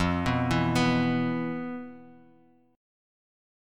E5/F Chord